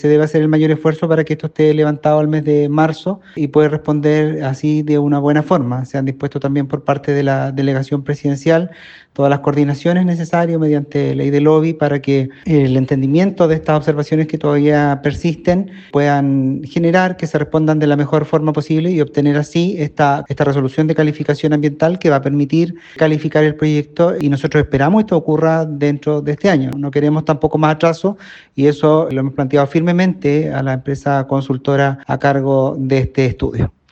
Al respecto, el seremi de Vivienda, Daniel Barrientos, afirmó que ya no quieren más retrasos y pidieron a la empresa consultora trabajar más, para responder a las observaciones de aquí a marzo.
cuna-guacamayo-seremi.mp3